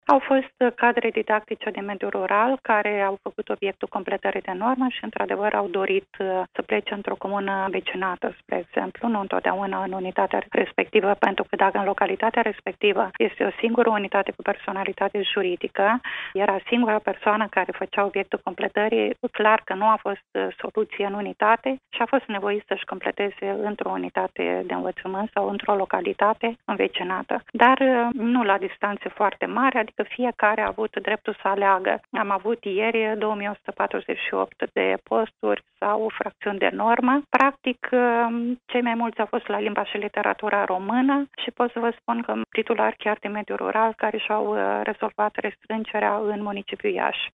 Șefa Inspectoratului Școlar Județean Iași, Rodica Leontieș, a  mai declarat, pentru postul nostru de radio, că au fost și cazuri în care profesorii sunt nevoiți să facă naveta pentru completarea normei.